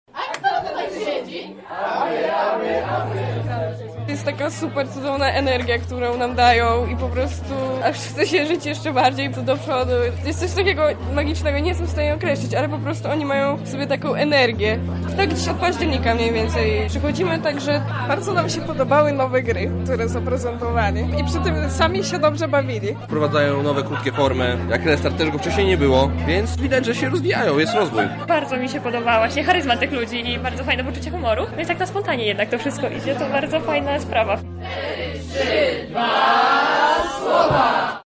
zapytała uczestników o wrażenia: